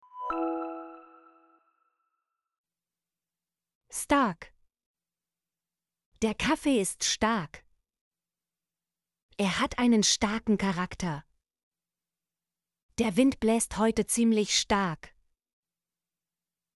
stark - Example Sentences & Pronunciation, German Frequency List